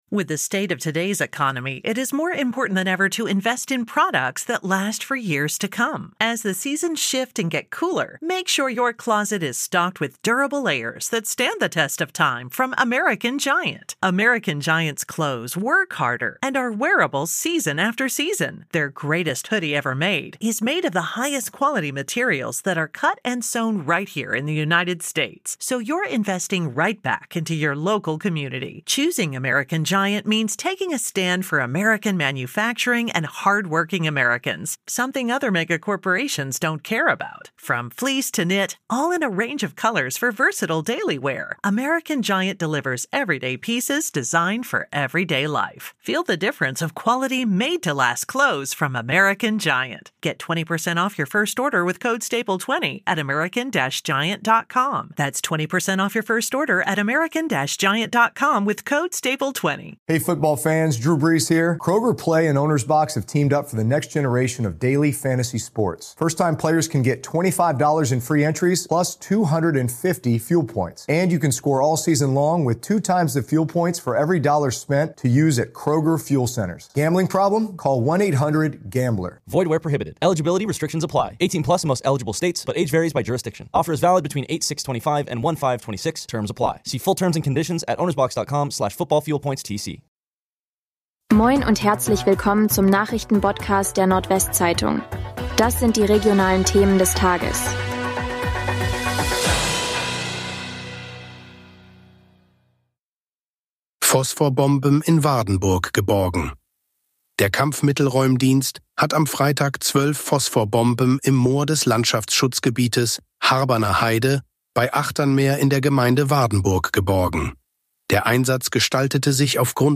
NWZ Nachrichten Botcast – der tägliche News-Podcast aus dem Norden